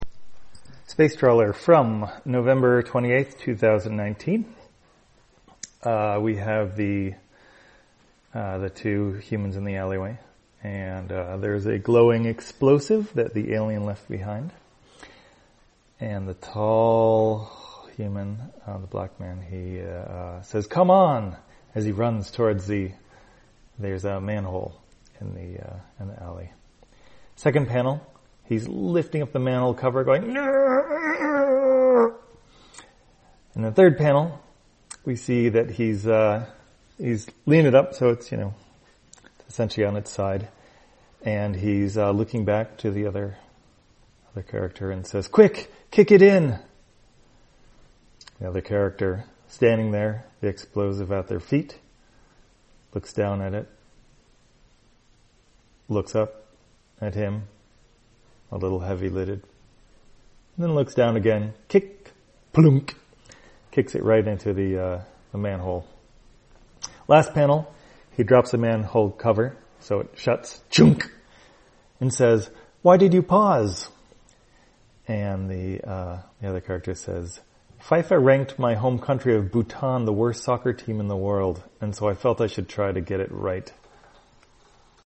Spacetrawler, audio version For the blind or visually impaired, November 25, 2019.